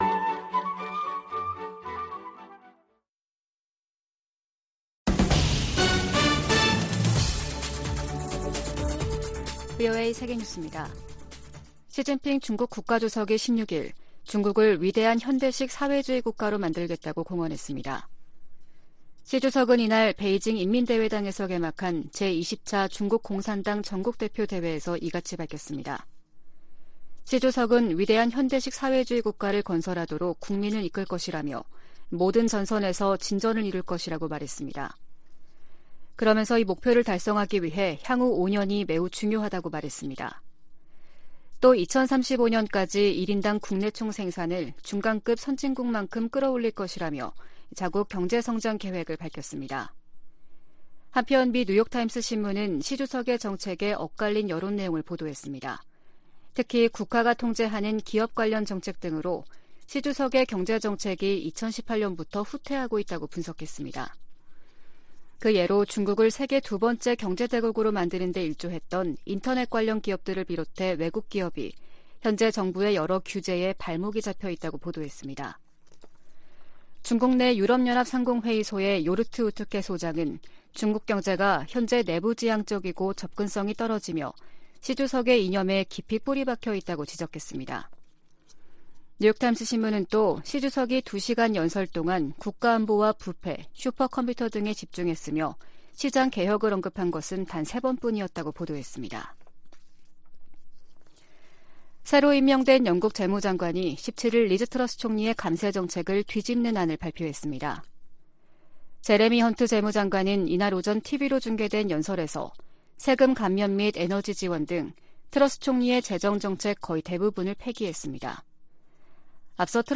VOA 한국어 아침 뉴스 프로그램 '워싱턴 뉴스 광장' 2022년 10월 18일 방송입니다. 북한이 연이어 9.19 남북 군사합의를 노골적으로 위반하는 포 사격에 나서면서 의도적으로 긴장을 고조시키고 있습니다. 미 국무부는 북한에 모든 도발을 중단할 것을 촉구하면서 비핵화를 위한 외교와 대화에 여전히 열려 있다는 입장을 재확인했습니다. 유엔은 북한의 안보리 결의 위반을 지적하며 대화 재개를 촉구했습니다.